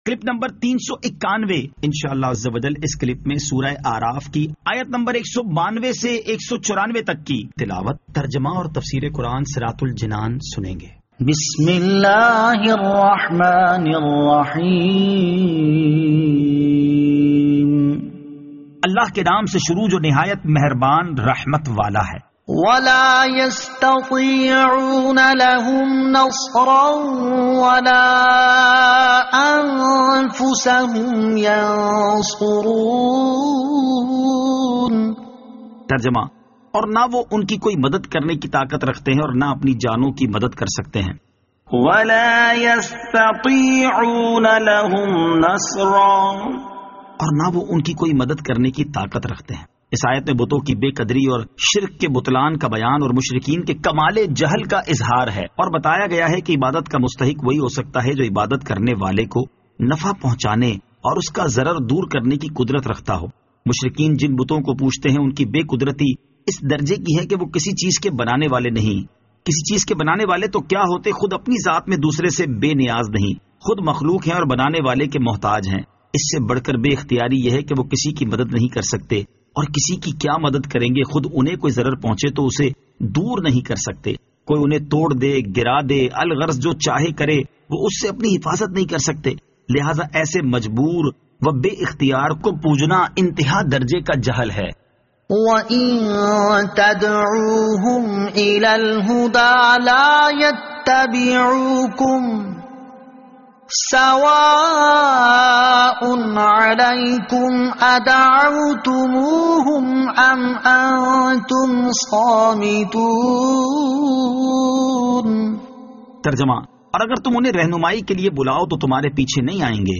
Surah Al-A'raf Ayat 192 To 194 Tilawat , Tarjama , Tafseer